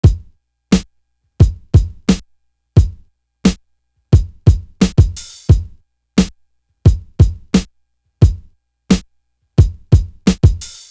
The Drama Drum.wav